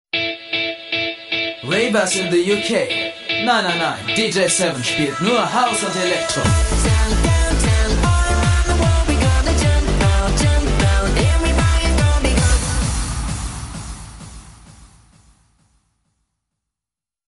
Jugendliche, dynamische, variable, markante, unverbrauchte, ausdrucksstarke, kräftige und facettenreiche Stimme.
Sprechprobe: eLearning (Muttersprache):
Young, energetic, variable, distinctive, expressive, powerful and multifarious voice.